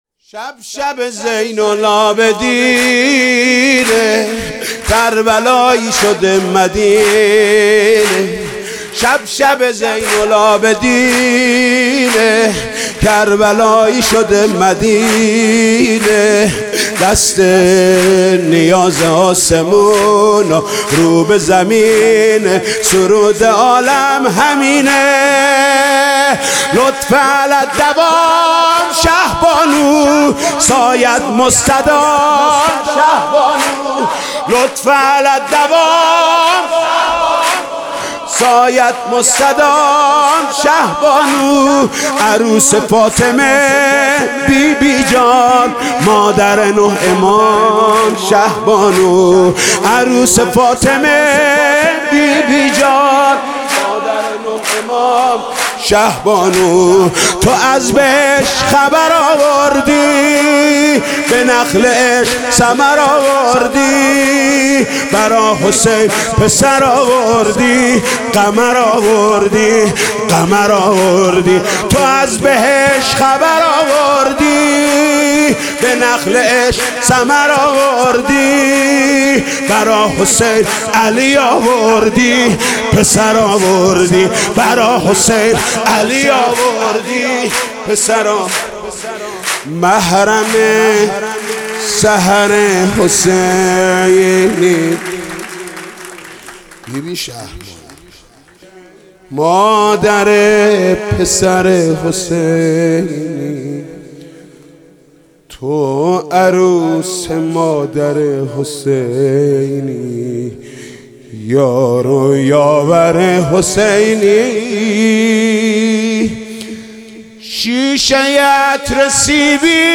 سرود: شب شبِ زین العابدینِ